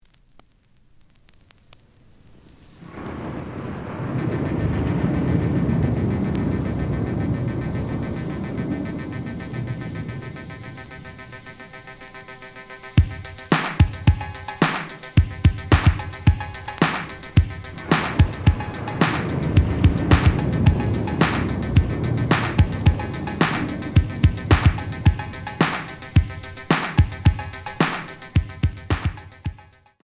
The audio sample is recorded from the actual item.
●Genre: Hip Hop / R&B